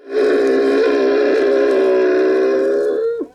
sounds_camel_01.ogg